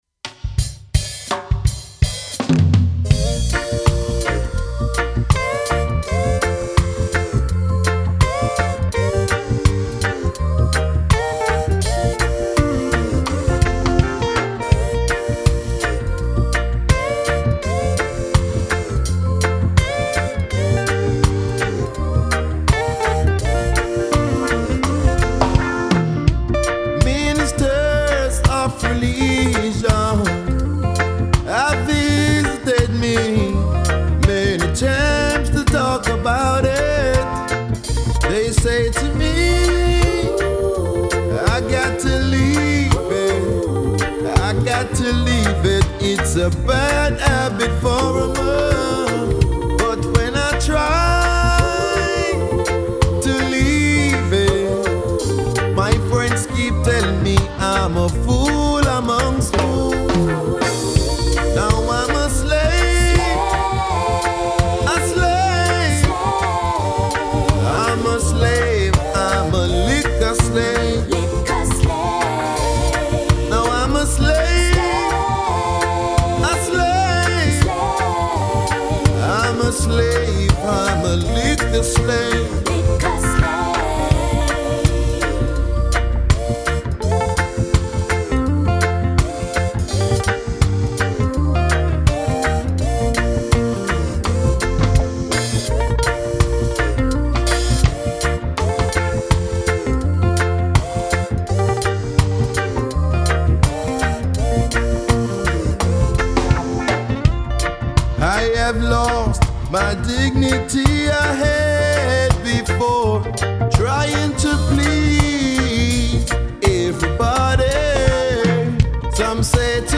regravação